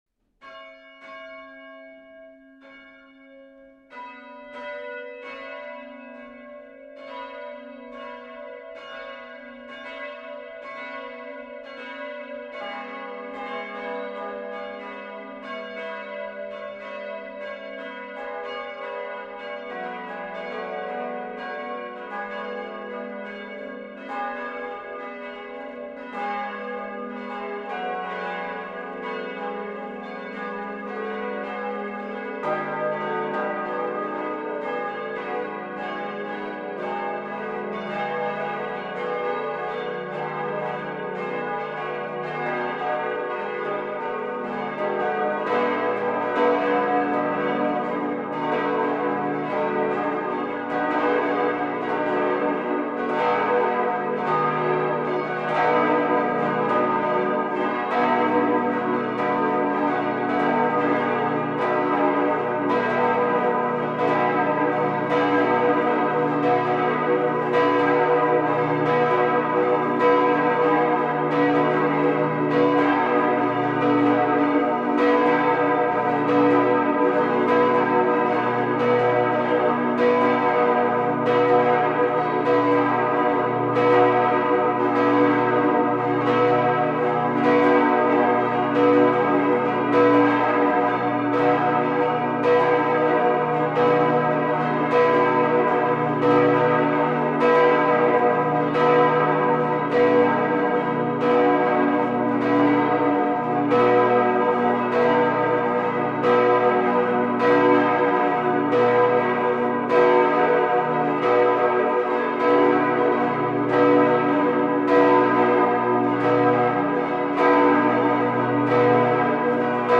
Presentem un enregistrament del plenum de les campanes de Santa Maria d´Igualada (Barcelona)
SANTA MARIA el bordó si2 (hº (=ut, en solmització o bé a la3=410Hz)) de 2980 kg i 169 cm de boca,
SANT CRIST D´IGUALADA re#3 (dis´(=mi)) de 2178 kg i 143 cm de boca,
VERGE DE LA PIETAT fa#3 (fis´(=sol)) de 1256 kg i 119 cm de boca,
Tot el conjunt (afinat segons la referència oficial la3=435Hz) està construït amb costella molt gruixuda (sehr schwere glockenrippe) per tal que les campanes donin el màxim de puresa sonora i d´harmònics, alhora que per augmentar-ne la ressonància per tal que el bordó (dotat d´una gran presència sonora, amb una octava inferior detectable durant més d´un minut i mig i construït amb costella mitjana - mittelschwere rippe) no les cobreixi ni anul·li.
La gravació es va fer emprant uns micròfons de gran resistència ubicats -contra tot pronòstic- a l´interior de la cambra de campanes i, per tant, sotmesos a una pressió sonora brutal( 160 dB o potser més).
igualada_plenum_13_2_2005.MP3 (clica aquí si el teu navegador no suporta html5)
Un problema que va donar-se és que les dues campanes grans, especialment el bordó, varen ofegar sonorament les restants -que eren situades més amunt i més lluny dels micròfons- cosa que no passa des del carrer.